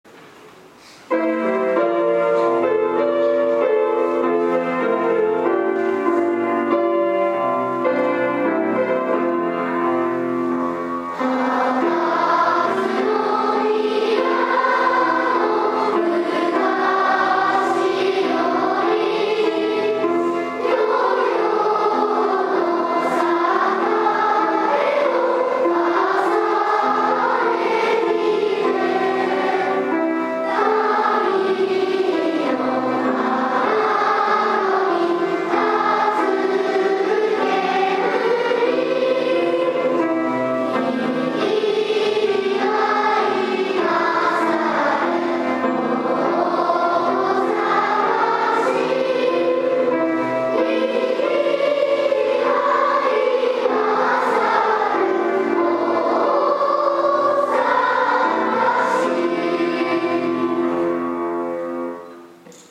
記念式典
大阪市歌斉唱